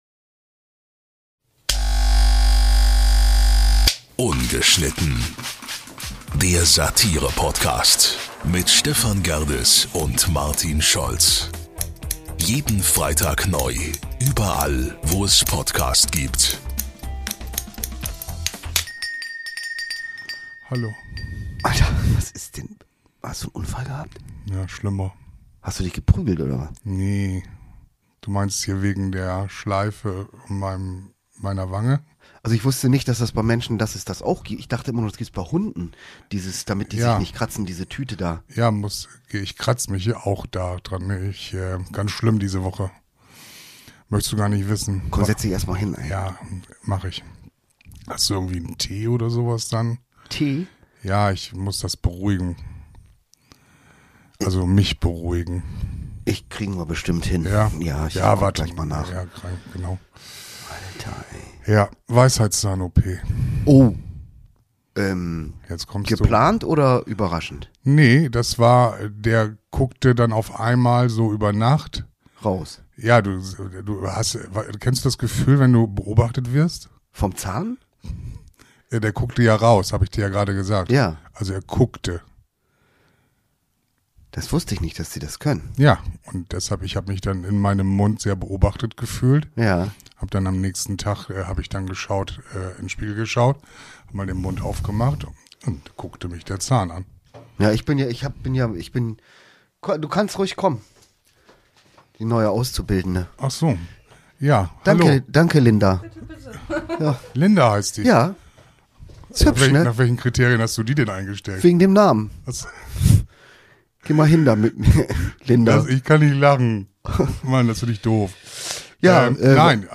Dazu gibt es 1a Dosenöffner Musik und unsere Verlosung.